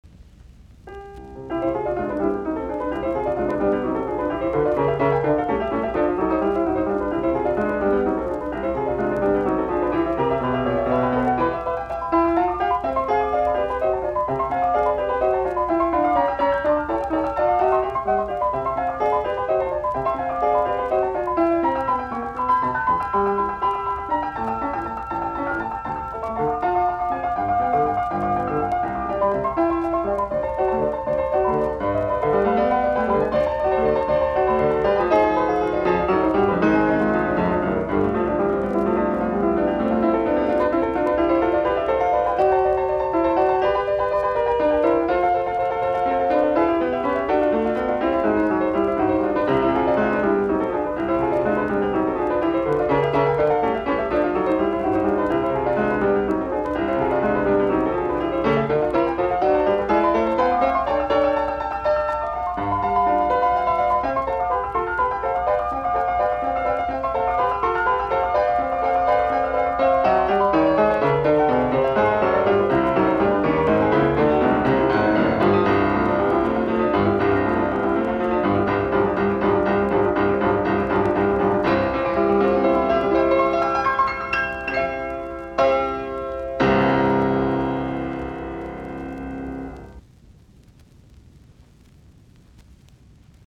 Etydit, piano, op10
Soitinnus: Piano.